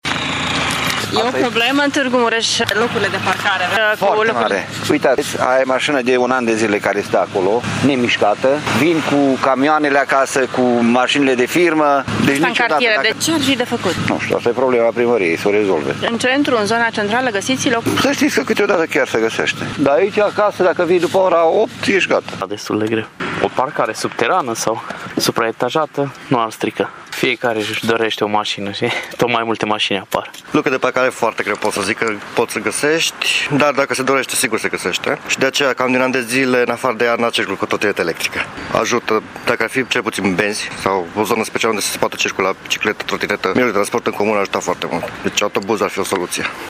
Târgumureșenii simt cel mai bine lipsa parcărilor, nu numai în zona centrală dar mai ales în cartiere. Soluția ar fi un transport în comun bine pus la punct, spun aceștia: